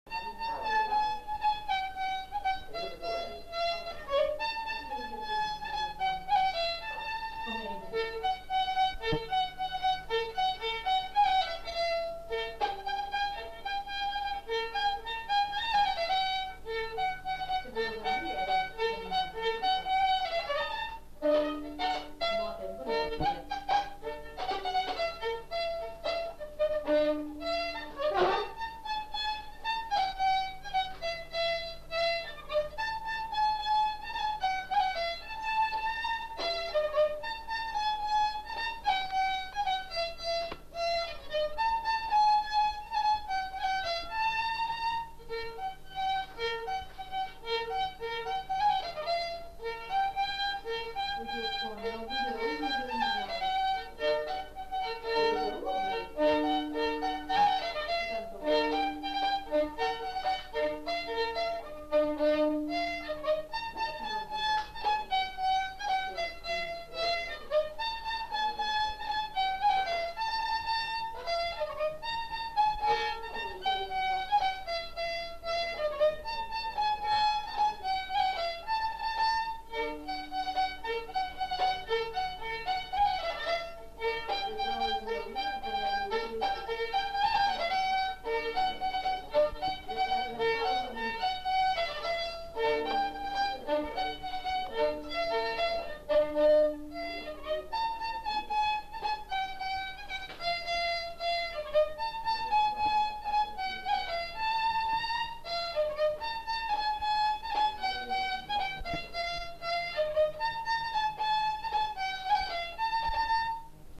Lieu : Saint-Michel-de-Castelnau
Genre : morceau instrumental
Instrument de musique : violon
Danse : polka